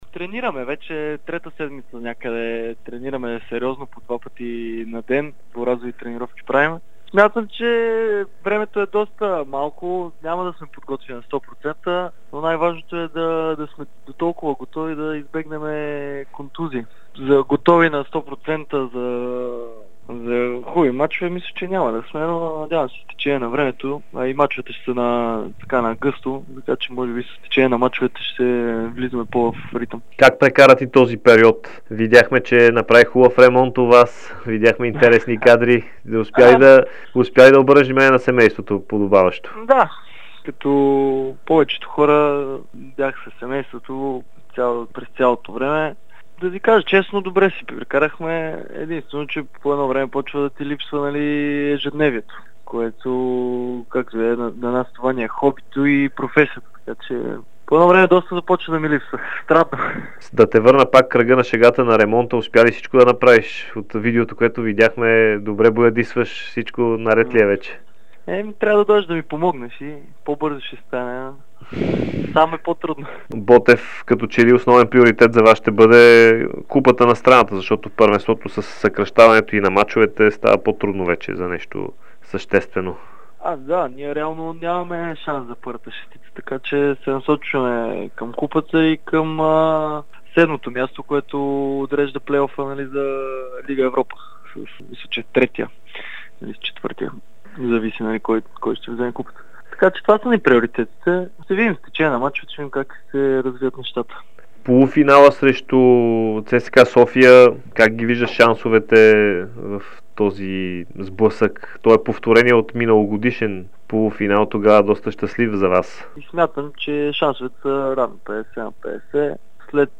Футболистът на Ботев Пловдив Антонио Вутов даде специално интервю за Дарик радио и dsport, в които сподели очакването си за началото на официалните мачове и целите пред "канарчетата".